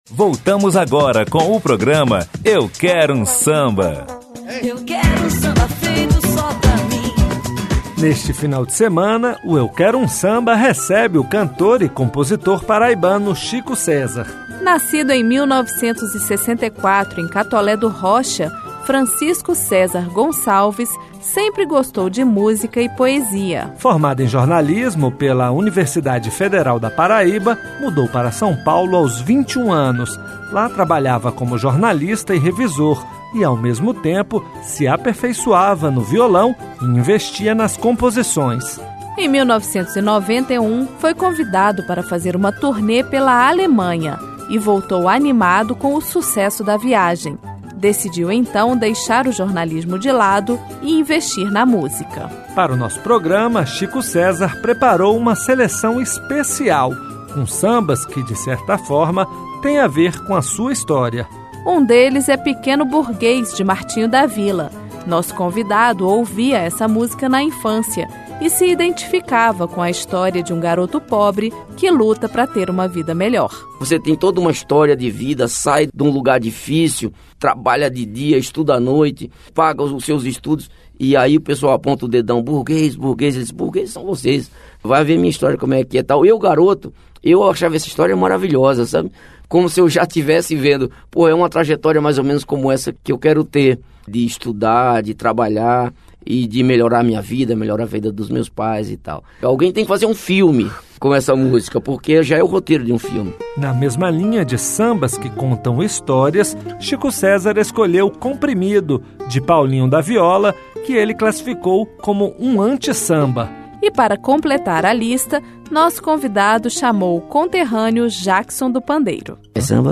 O cantor e compositor paraibano Chico César é o convidado deste final de semana do programa Eu quero um samba, da Rádio Senado.